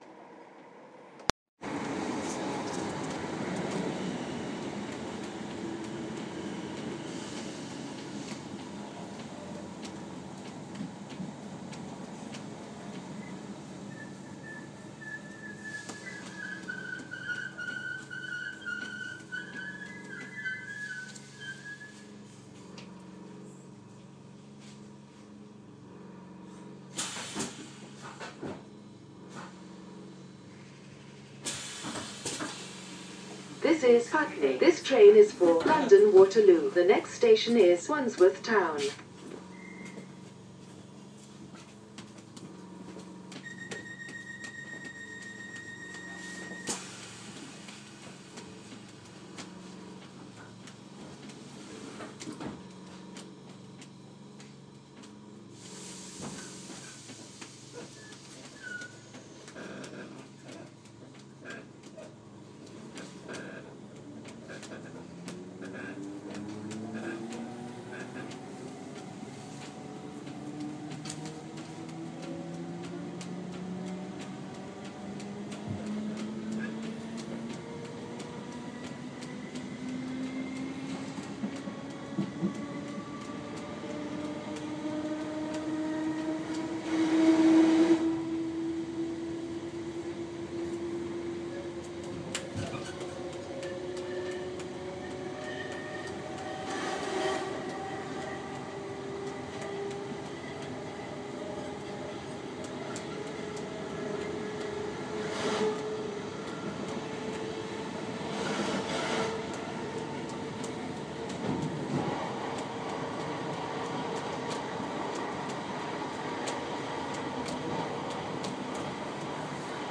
A fellow passenger practising drumming rhythms on the train to Waterloo